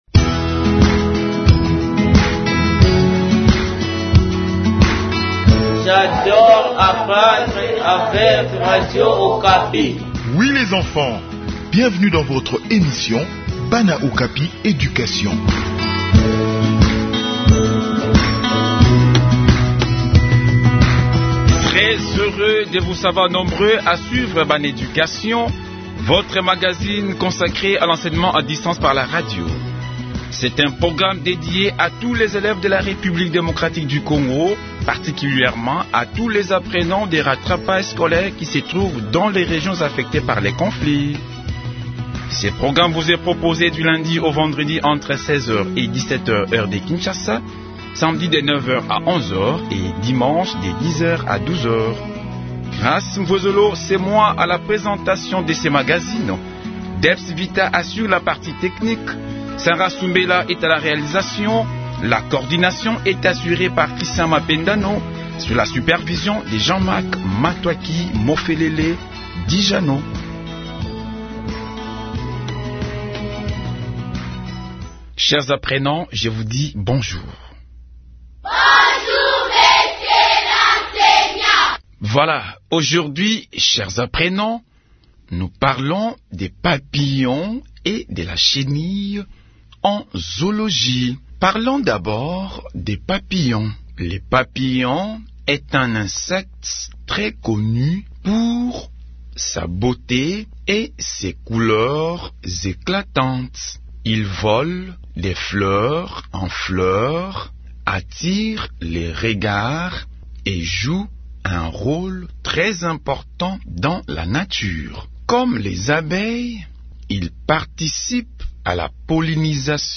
Enseignement à distance : leçon de zoologie sur la chenille et le papillon